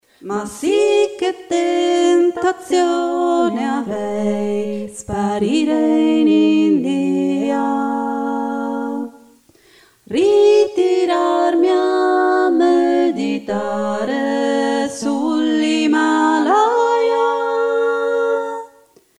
The high voice was created in Melodyne.
HD-trio-voices.mp3
voice & background voices